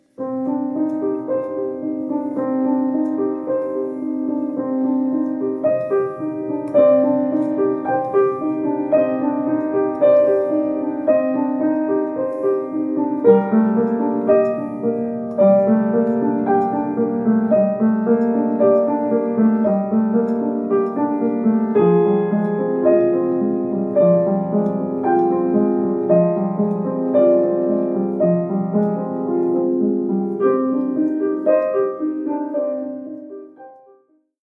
Collection : Piano
Oeuvre pour piano solo.